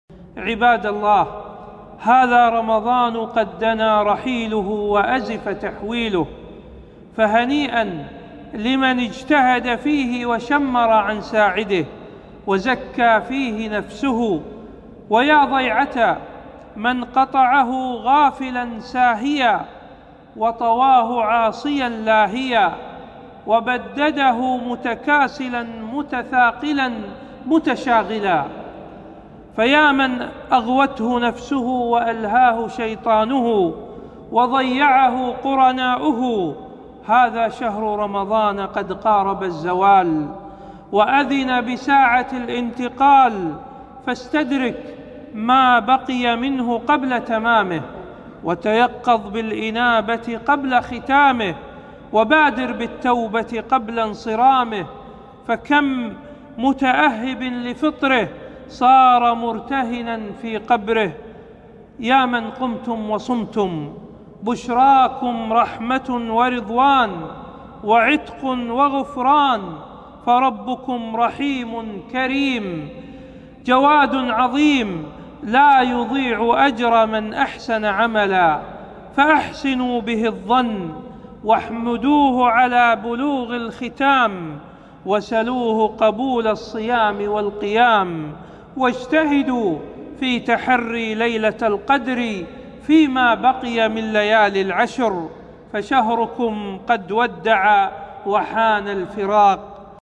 مقتطف - موعظة إيقاظُ الهِمم لمن أراد أن يغتنم